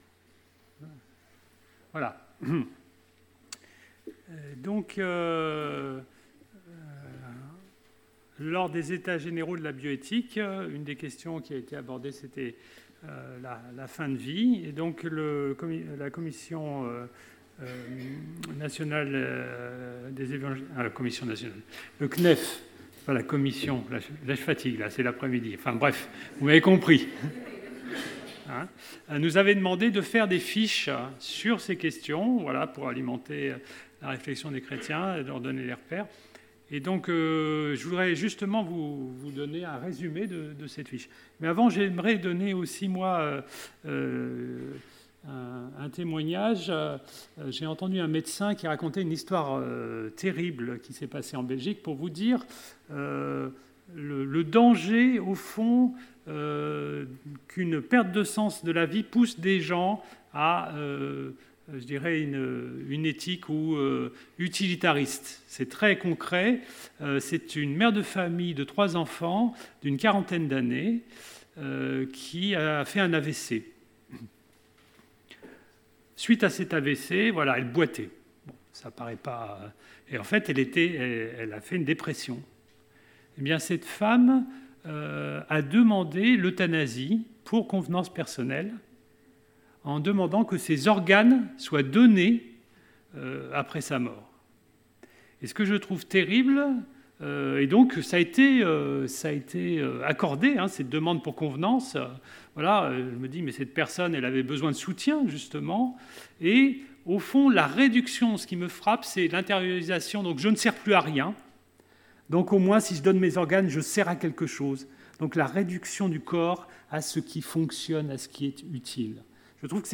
Le 1er novembre 2018 à l’Eglise d’Altkirch, la rencontre inter-Eglises mennonites a rassemblé environ 150 personnes.